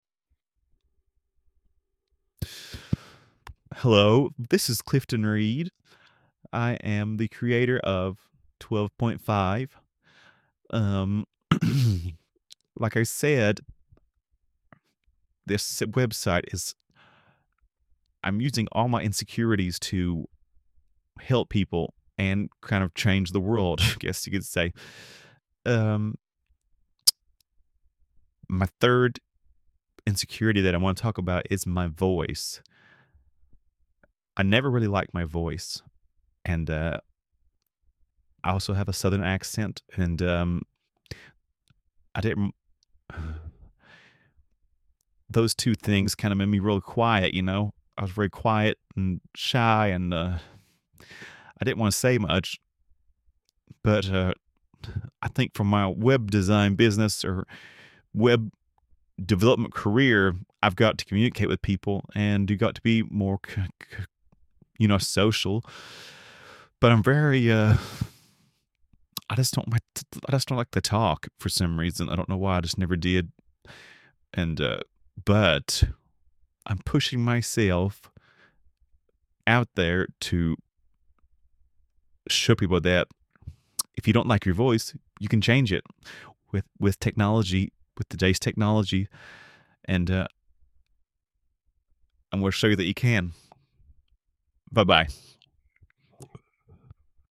I used a service by Eleven Labs.